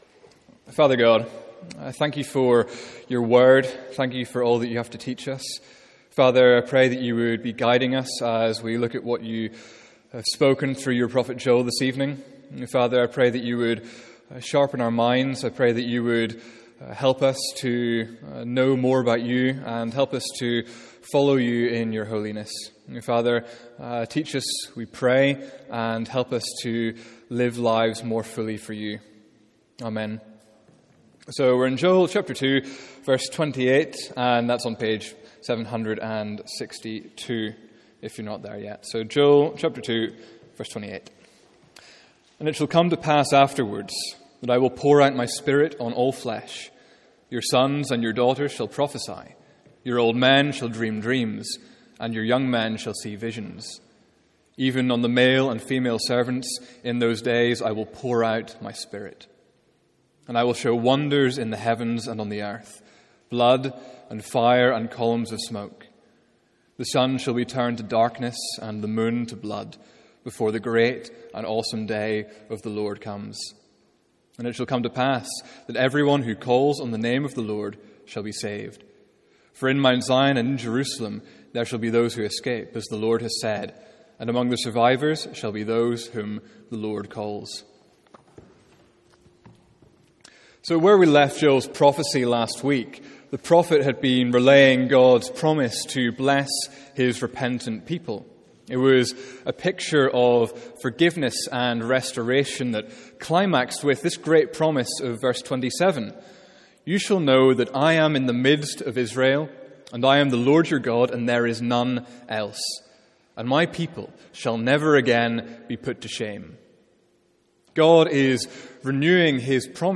Sermons | St Andrews Free Church
From our evening series in Joel.